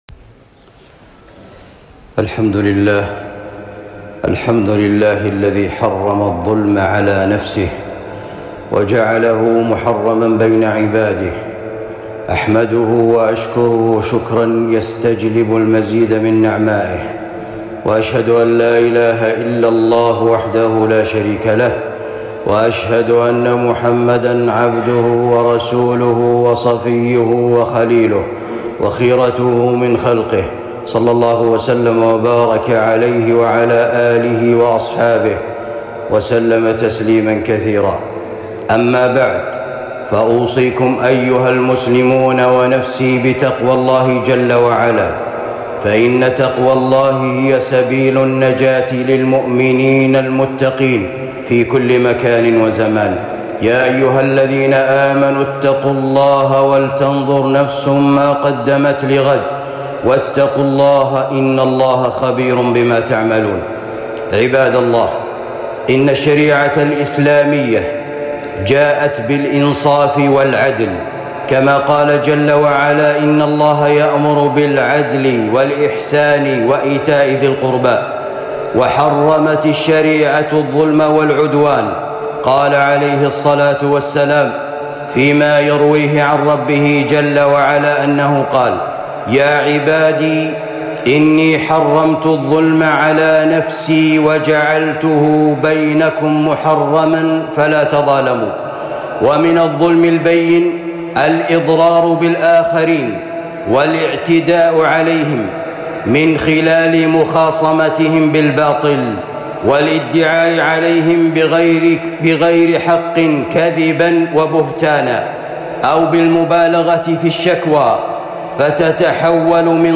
خطبة بعنوان الشكاوى الكيدية - خطرها ومفاسدها